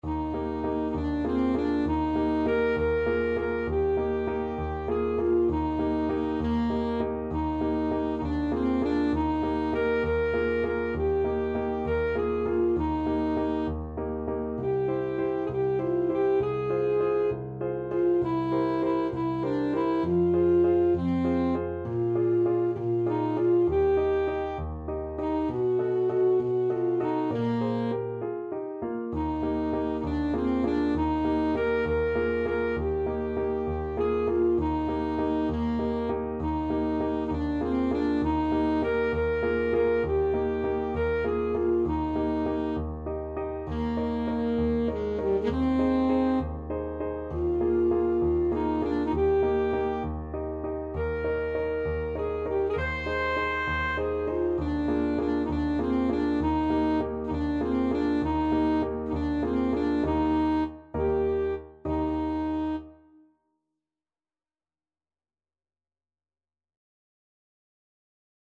Alto Saxophone
6/8 (View more 6/8 Music)
. = 66 No. 3 Grazioso
Eb major (Sounding Pitch) C major (Alto Saxophone in Eb) (View more Eb major Music for Saxophone )